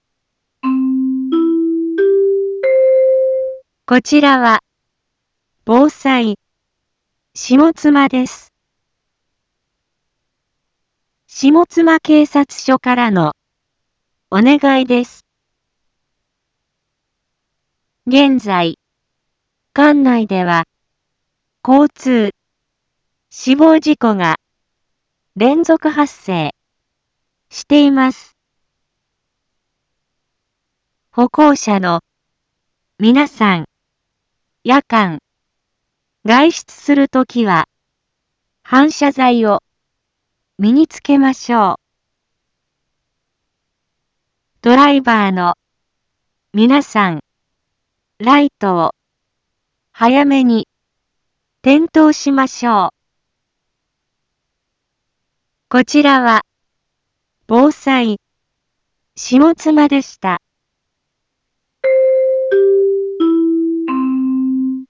一般放送情報
Back Home 一般放送情報 音声放送 再生 一般放送情報 登録日時：2022-12-16 17:31:08 タイトル：交通死亡事故連続発生にかかる事故防止広報 インフォメーション：こちらは、防災、下妻です。